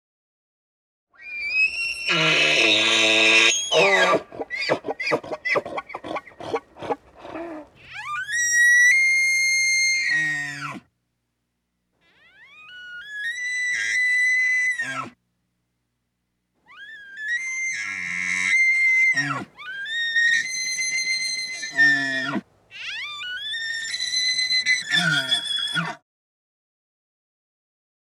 animal
Elk Squeals